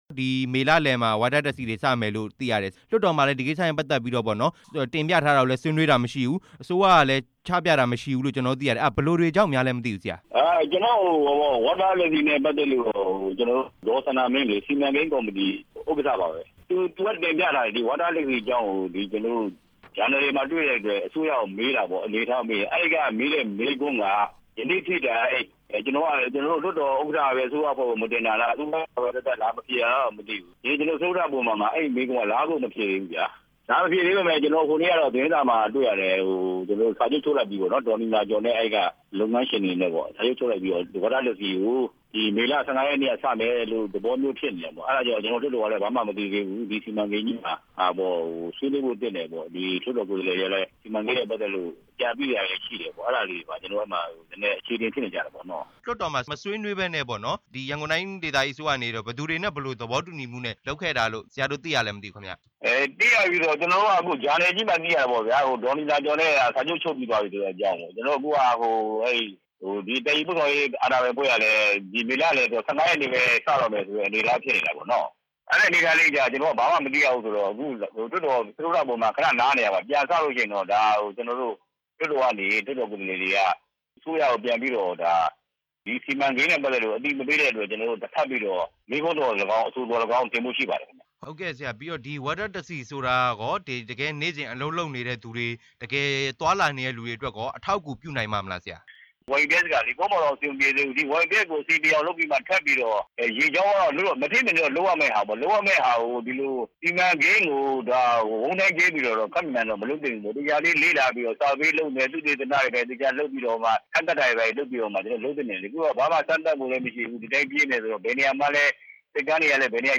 Water Taxi ပြေးဆွဲမယ့် အကြောင်း မေးမြန်းချက်